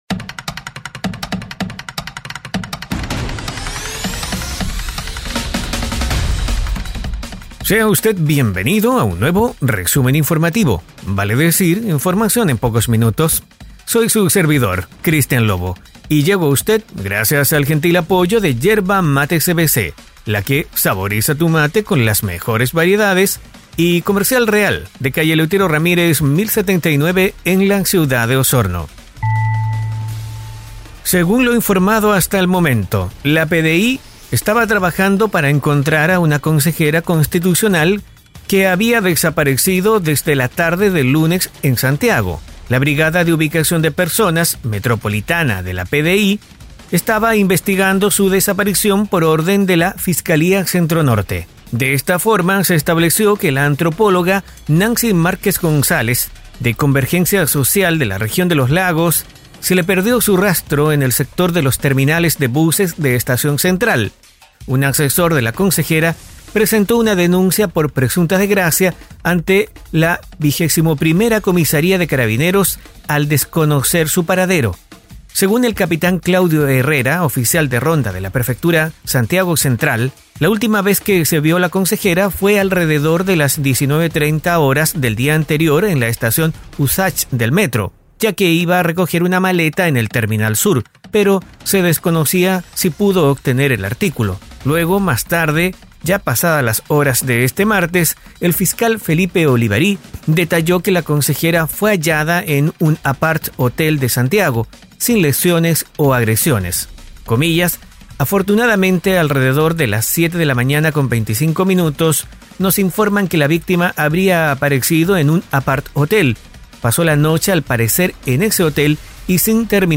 🗞🌊 En cada episodio, te brindaré las noticias más relevantes de la región en tan solo unos minutos.